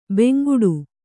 ♪ benguḍu